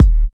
sfg_sub_kick.wav